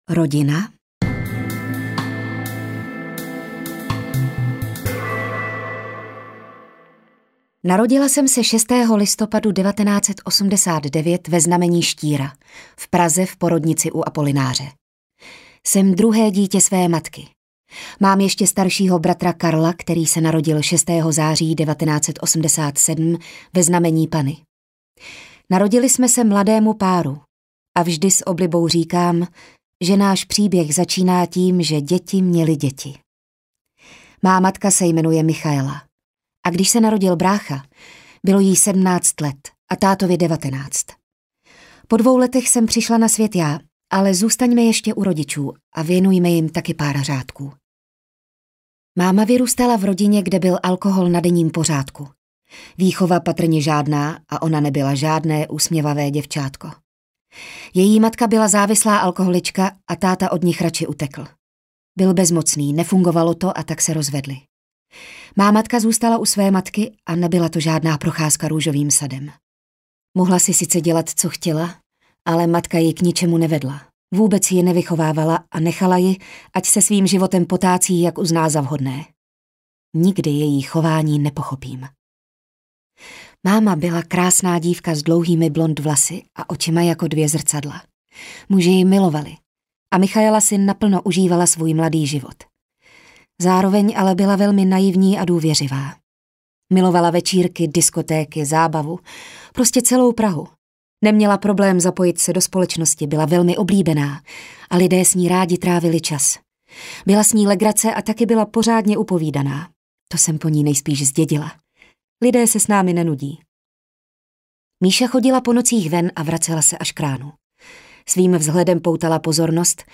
Zpověď: Z děcáku až na přehlídková mola audiokniha
Ukázka z knihy